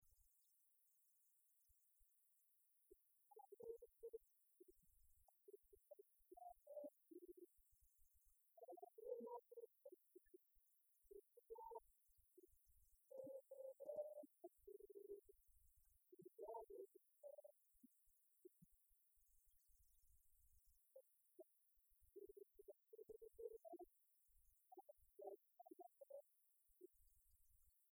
strophique
Concert de la chorale des retraités
Pièce musicale inédite